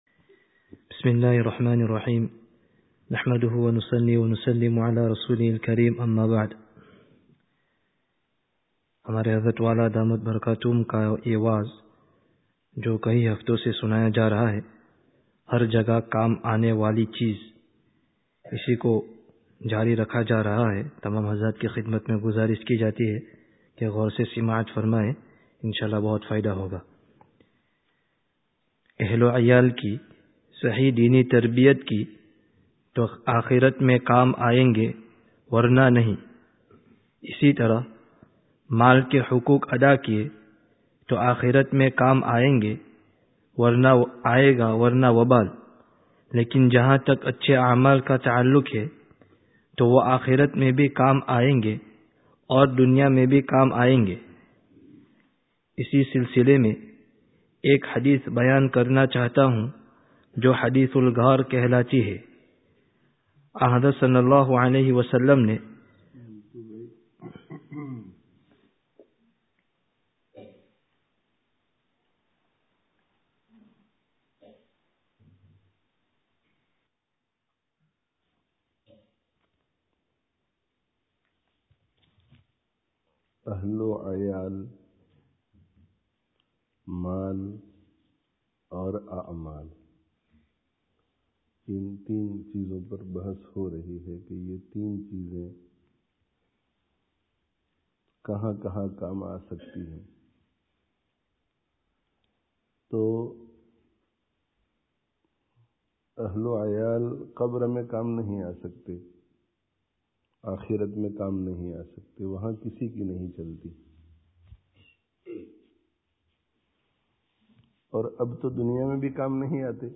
Download 3rd saturday 2013 wa'z bil-kitab friday tazkiyah gathering Related articles Wa'z Bil-Kitāb: Neyk Suhbat se Fā'idah kyu(n) nahi(n)?